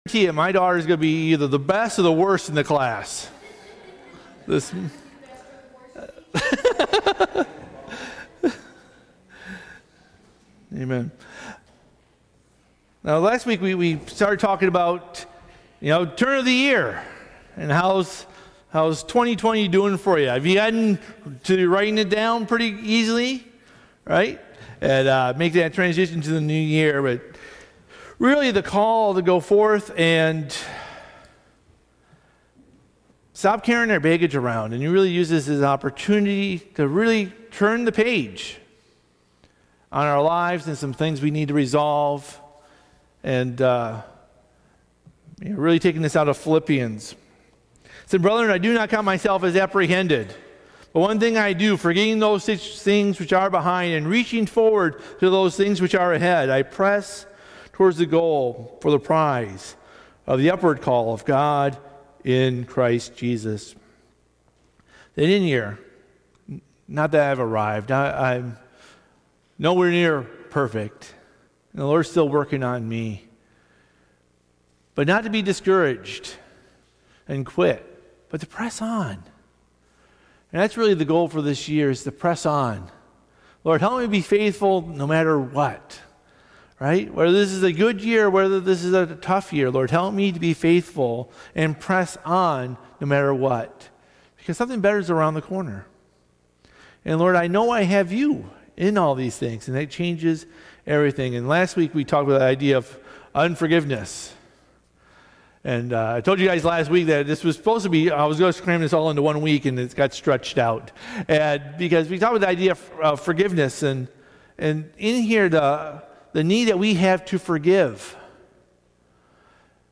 Bible Text: Philippians 3:13-14 | Preacher